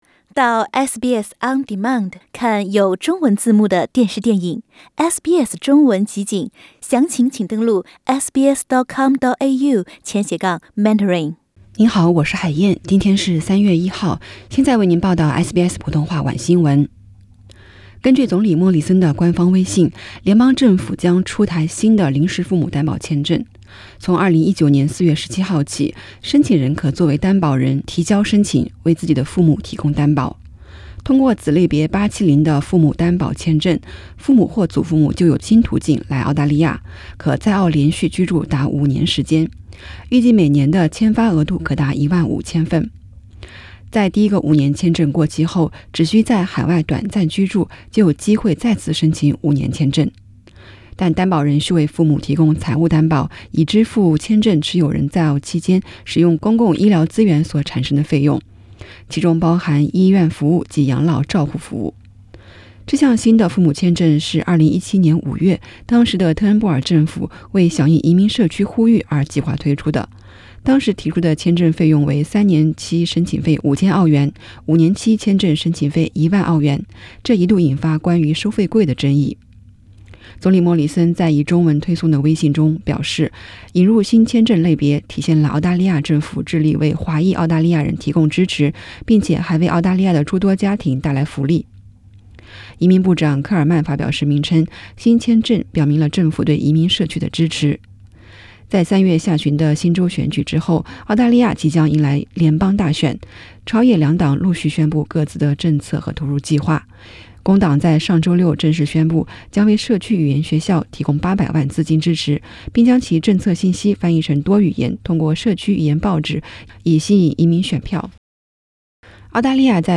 SBS晚新闻（3月1日）